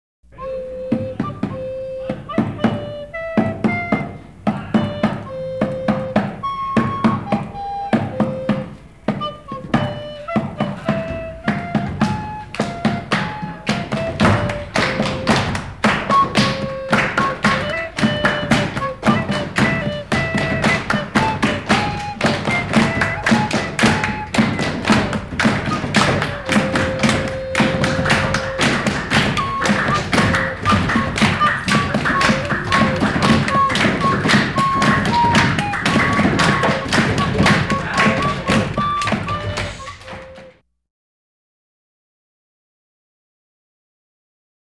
88. Flauta y tambor Public